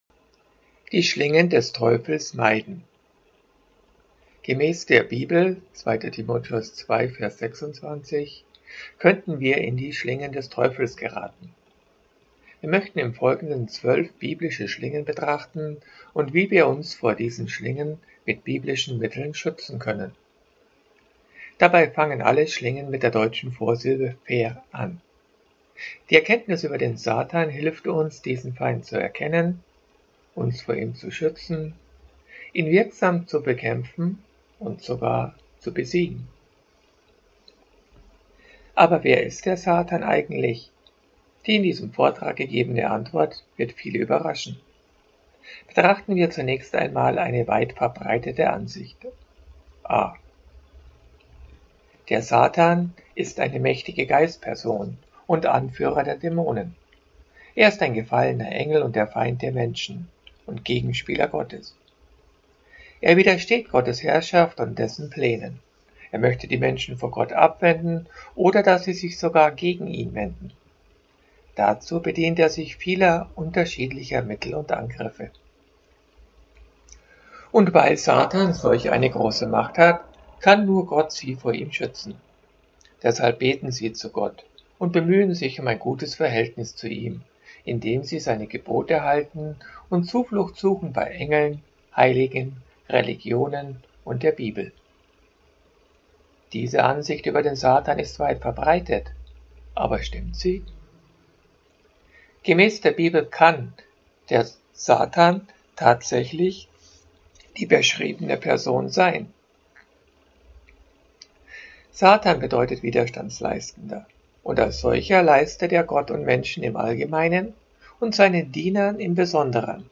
Verblendung Vortrag als pdf Vortrag zum Anhören